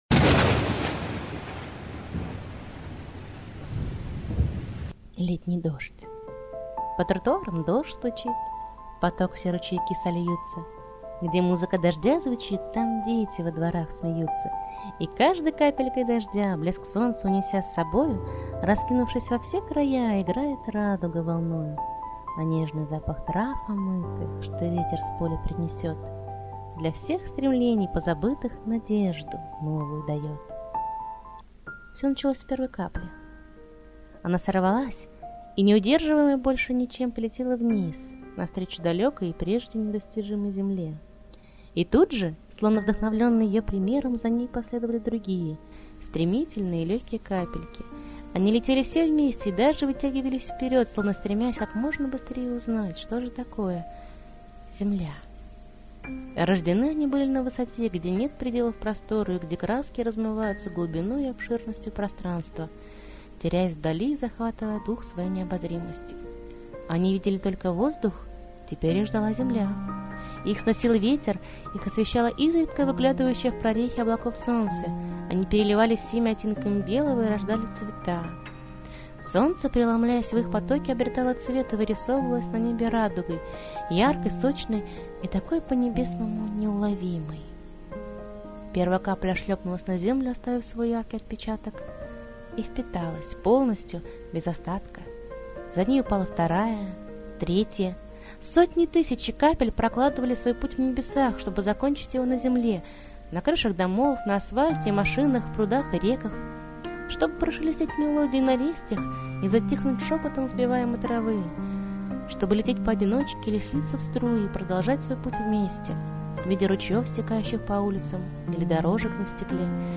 letnijdozhdx-2.mp3 (8215k) авторское чтение в формате .MP3 Я